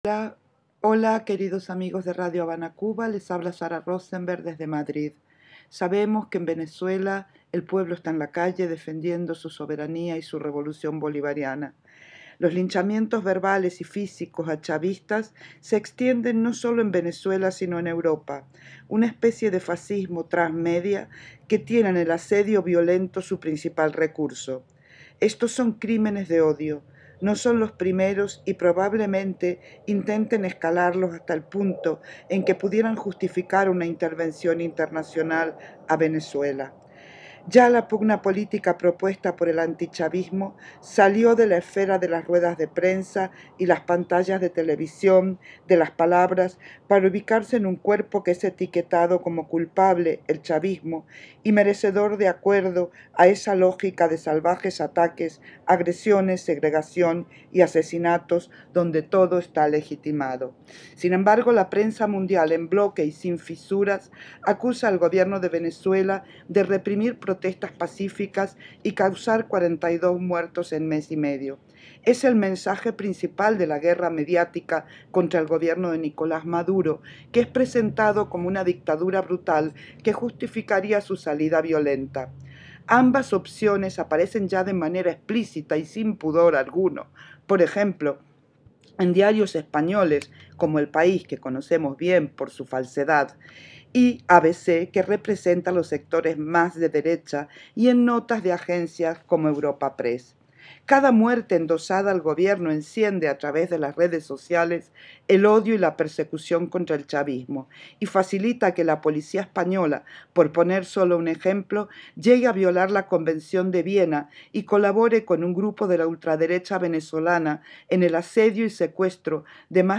para Radio Habana, Cuba